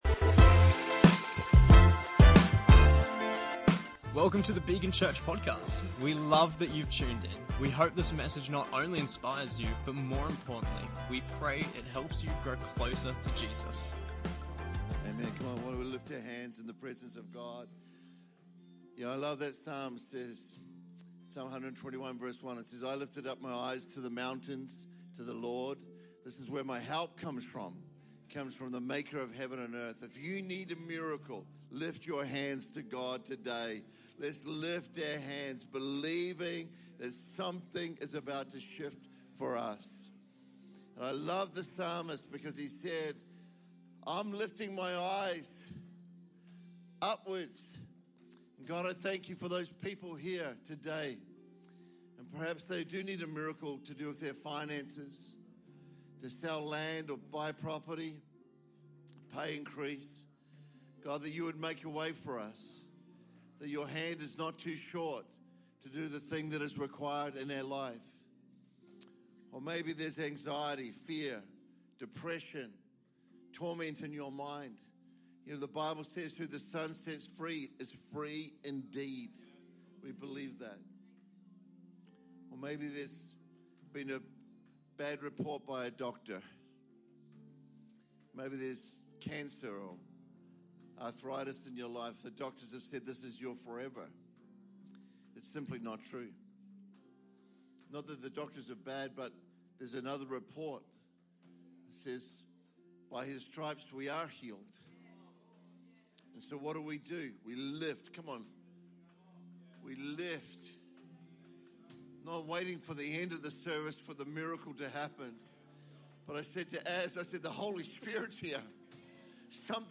Episode 124: Guest Speaker